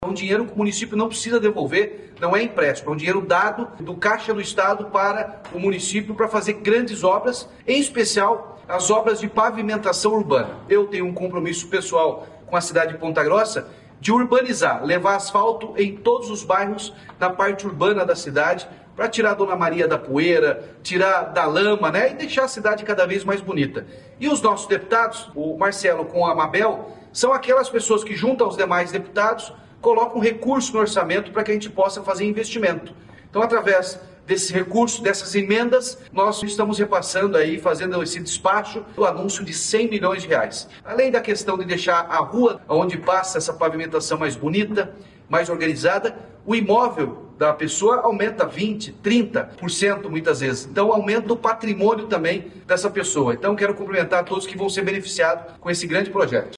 Sonora do governador Ratinho Junior sobre o anúncio de R$ 100 milhões para obras de pavimentação em Ponta Grossa